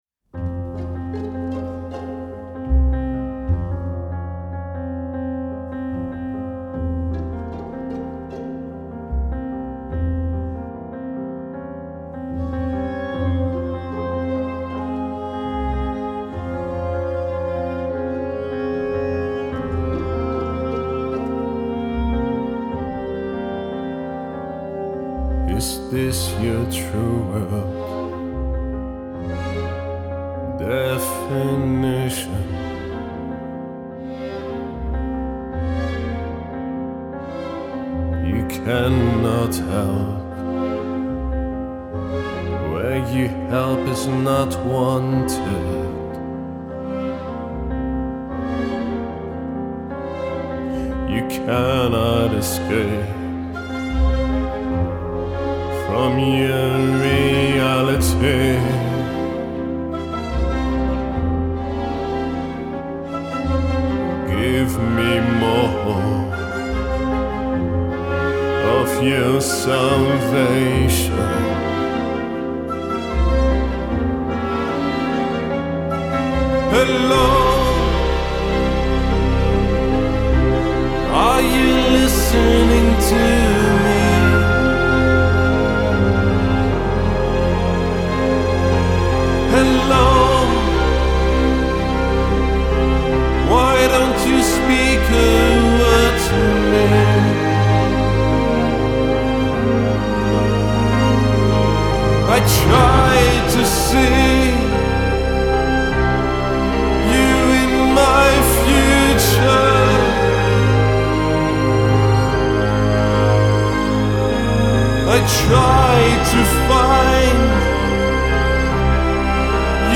indie Rock